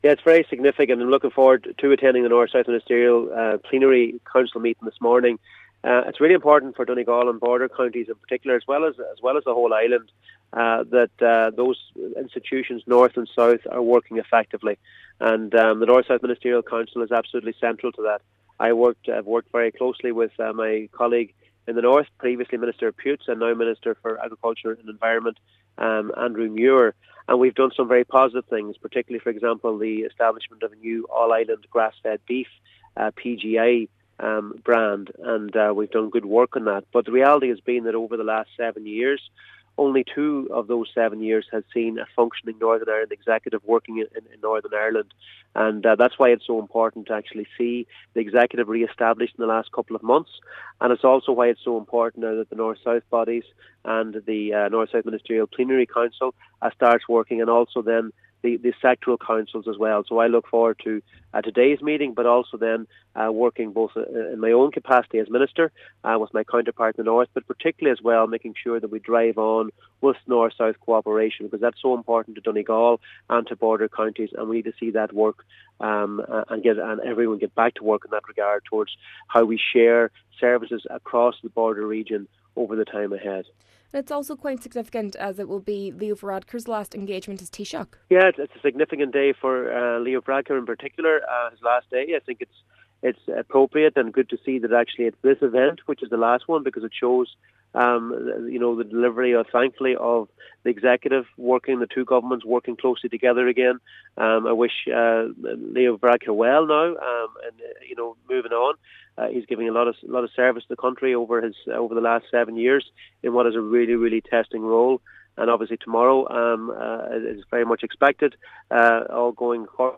Minister for Agriculture, Food and the Marine says today’s meeting of the North South Ministerial Council is doubly significant.